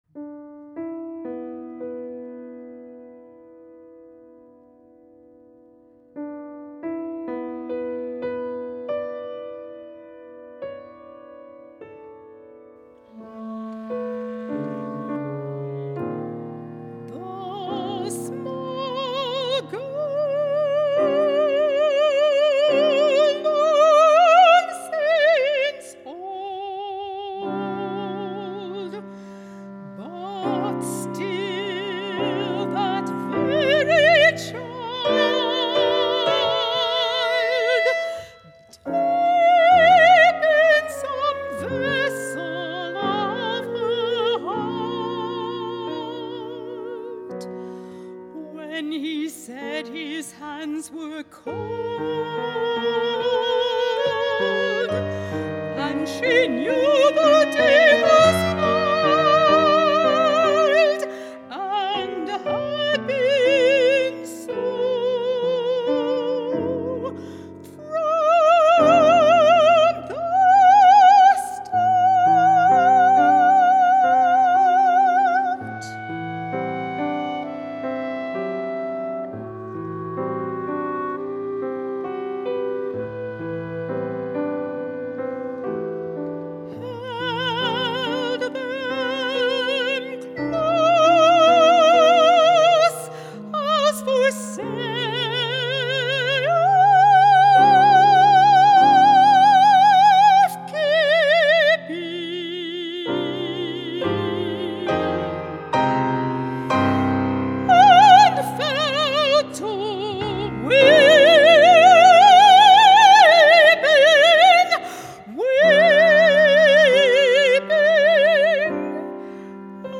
Soprano, Clarinet in A & Piano